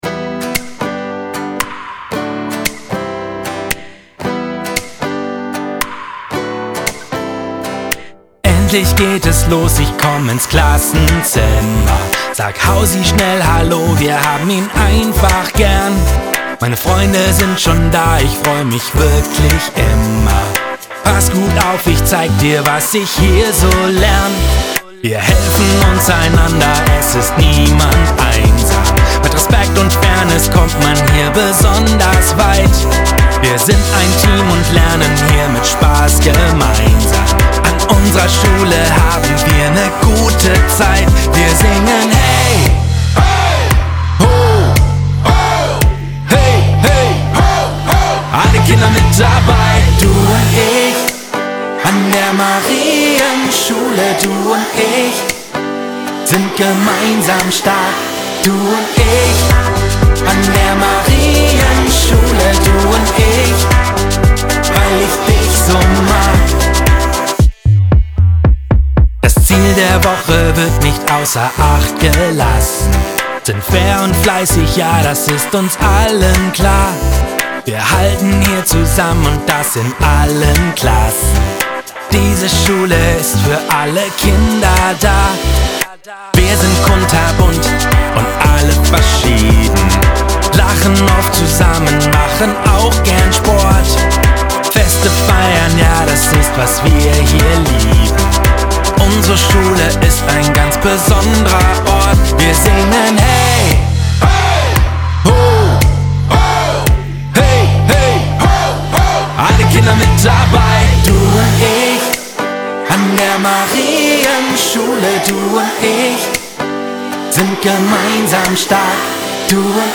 von den "Minimusikern" aus Münster eingespielt